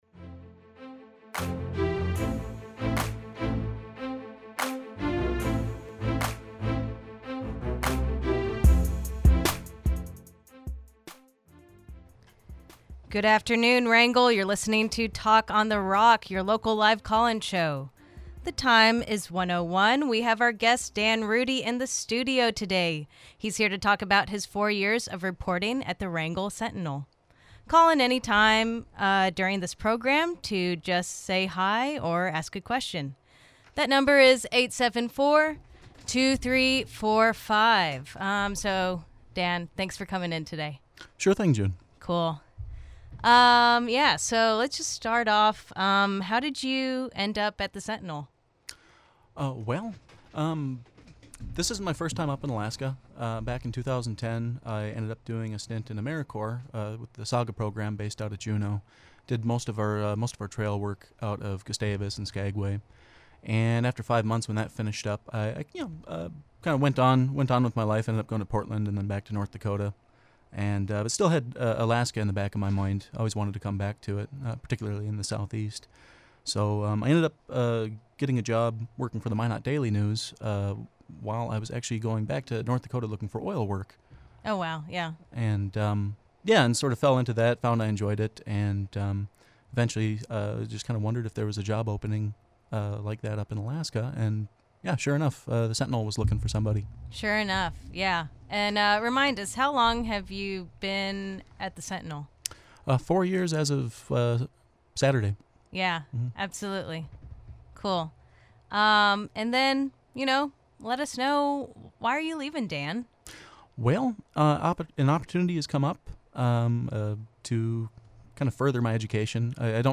Talk on the Rock is Wrangell's live call-in show.
Talk on the Rock is KSTK’s live call-in show. Every Tuesday at 1pm we welcome a local guest to discuss events and topics of interest to our little island town.